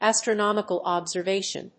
astronomical+observation.mp3